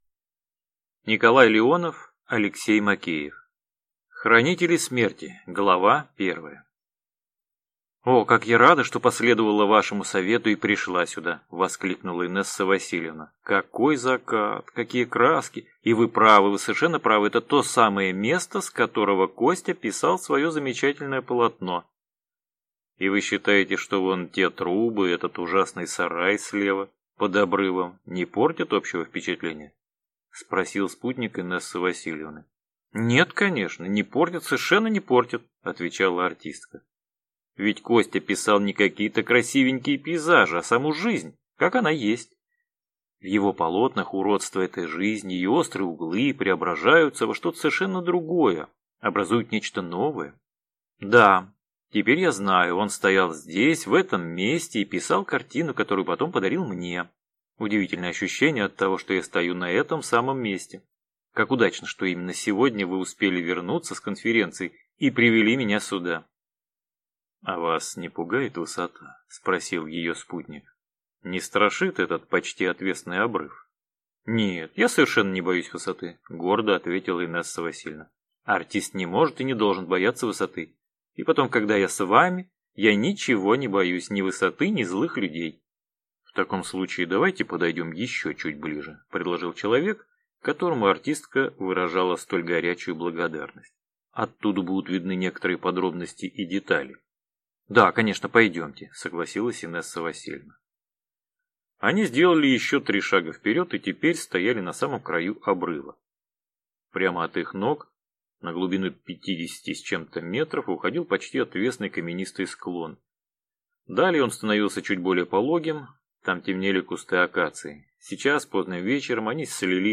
Аудиокнига Хранители смерти | Библиотека аудиокниг